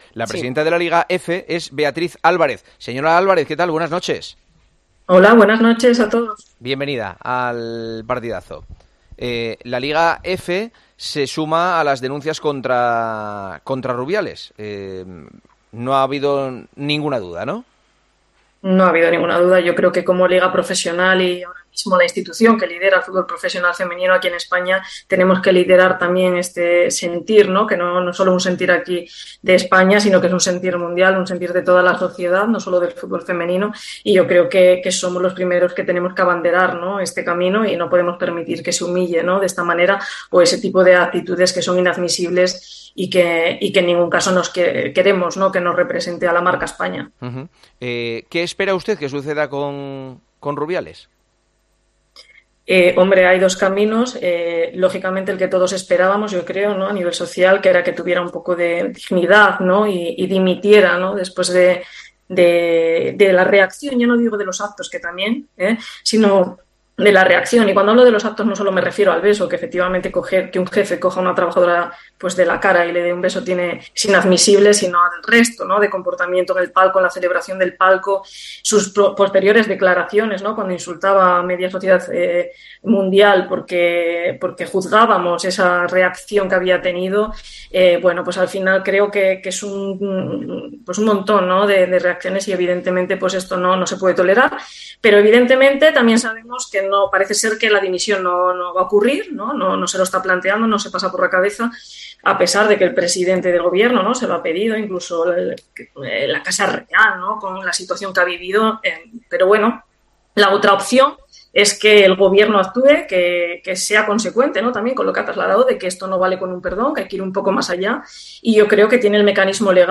La Presidenta de LaLiga F ha hablado este miércoles en El Partidazo de COPE, horas después de que la Liga Femenina emitiese un comunicado denunciando a Luis Rubiales.